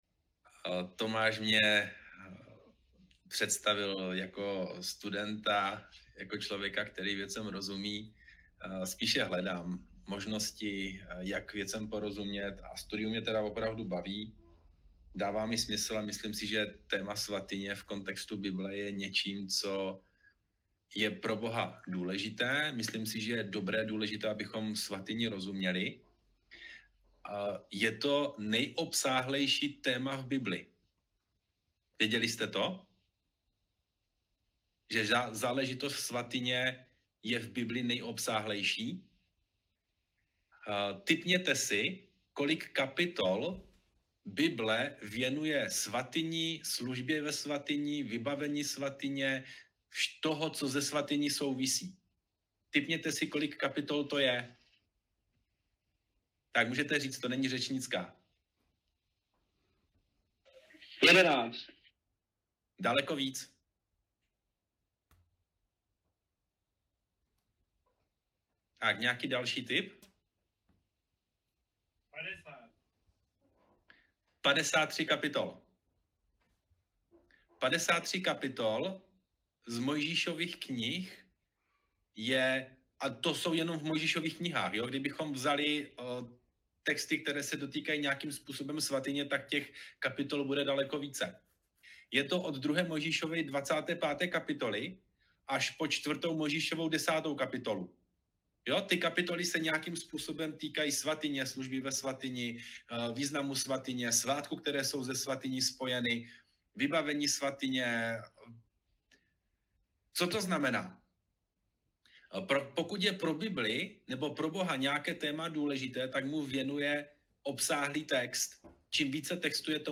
Třetí přednáška z cyklu ČEMU VĚŘÍ ADVENTISTÉ